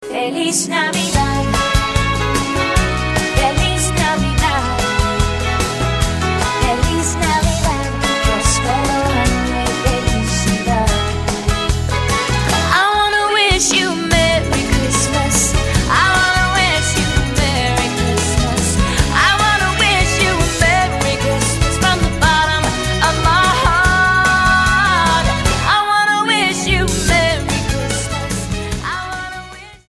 STYLE: Orchestral